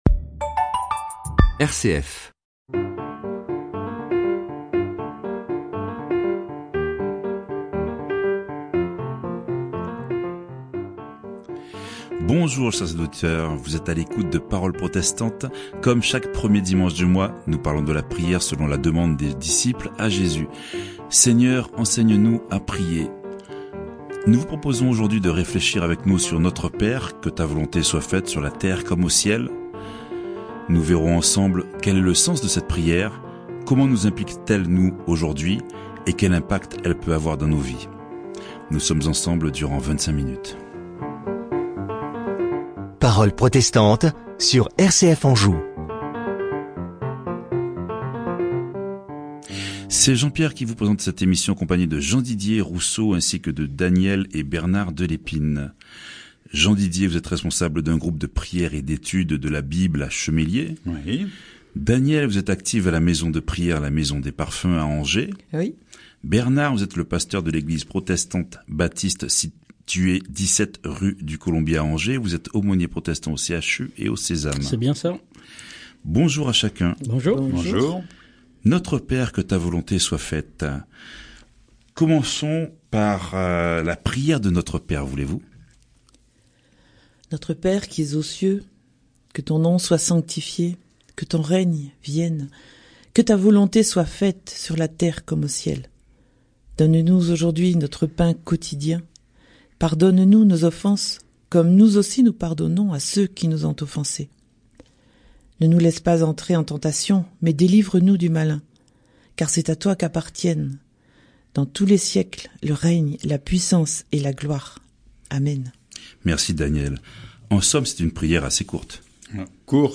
Séries : Emission de radio RCF | La prière dite du « Notre Père » est parfois tellement récitée que l’on a perdu le sens de chaque déclaration.